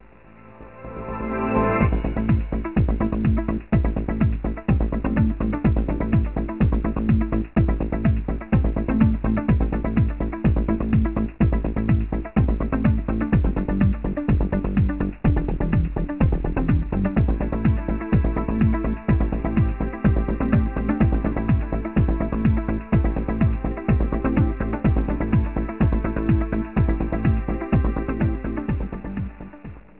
one of the new album's instrumentals